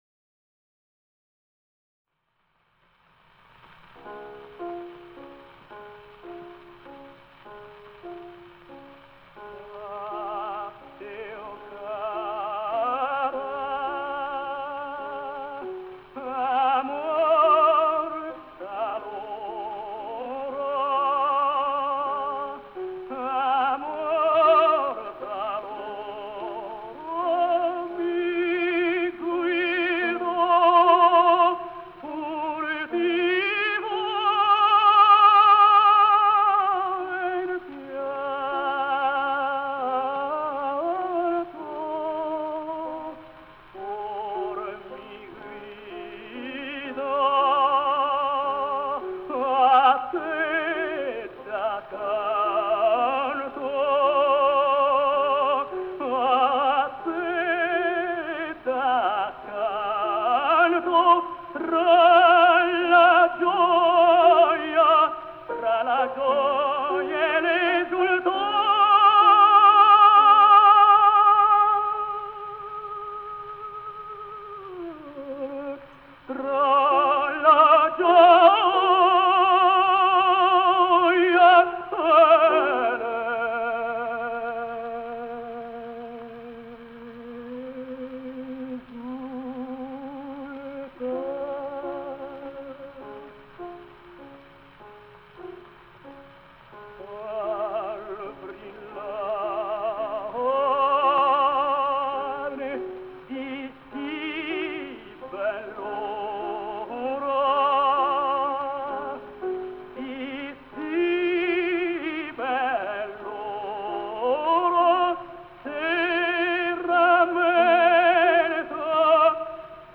Этим записям более ста лет. Итальянский тенор Alessandro Bonci. Арии из опер (1905 - 1907).